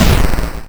ihob/Assets/Extensions/explosionsoundslite/sounds/bakuhatu26.wav
bakuhatu26.wav